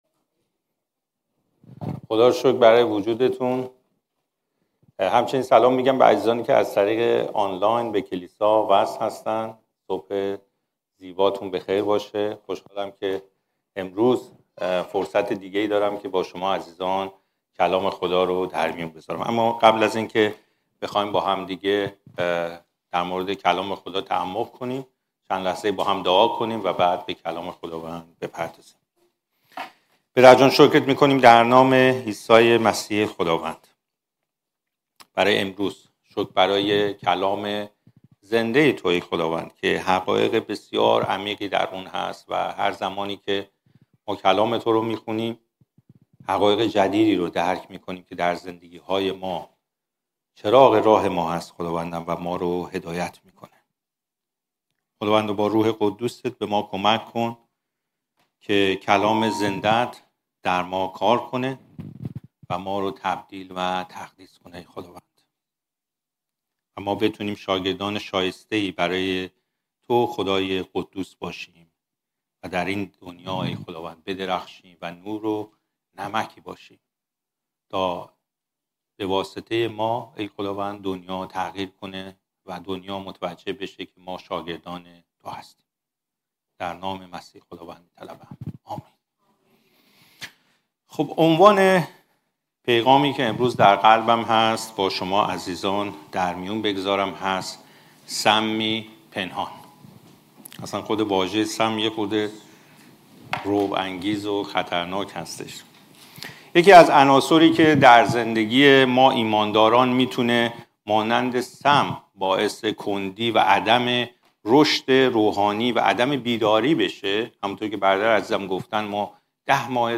موعظه‌ها